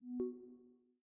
Reduce lock / unlock sound level ... Loud enough to be heard, low enough not to wake up people next to you at night.
Unlock.ogg